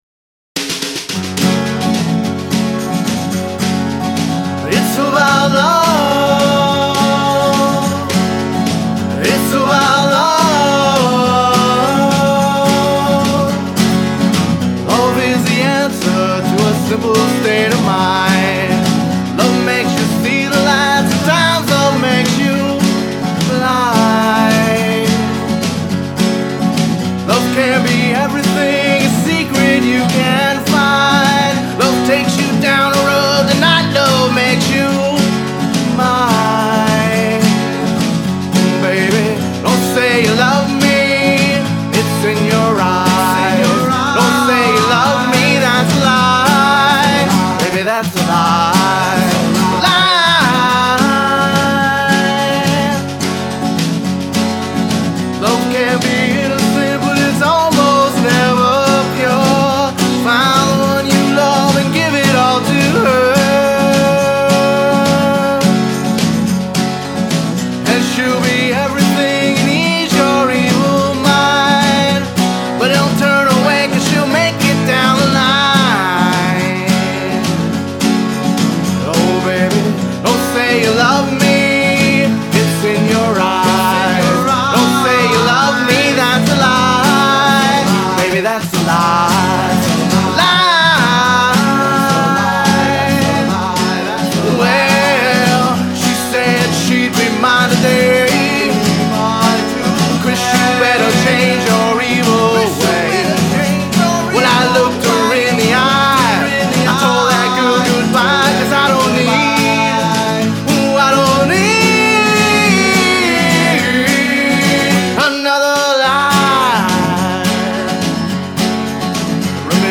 Vocals, Guitars, Bass, Harmonica, Trumpet
Percussion
Bass, Keyboards